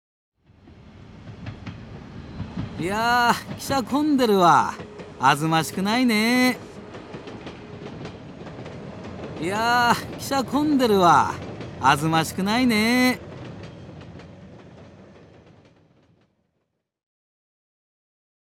北海道弁を読み上げる“新感覚かるた”！
BGM・効果音付きで楽しさ倍増